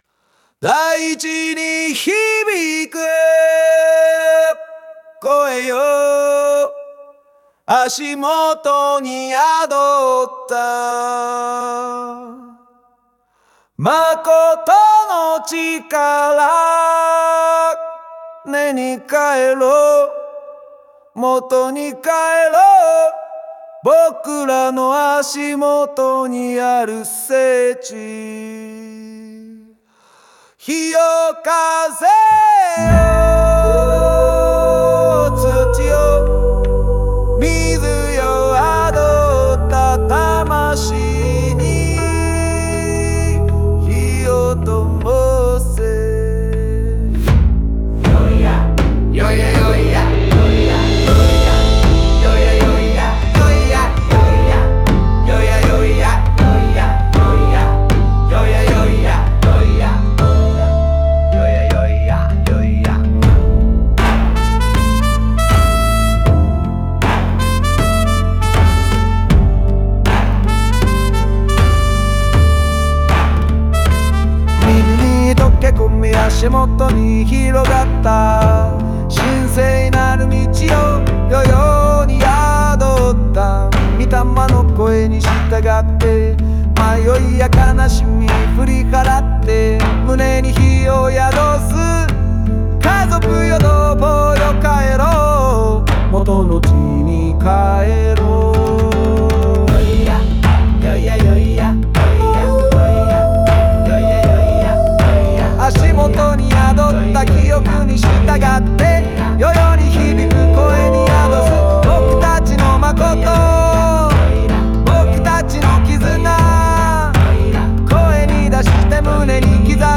語りかけるような力強い声質と、和楽器の音色が融合し、魂に響く精神性を生み出しています。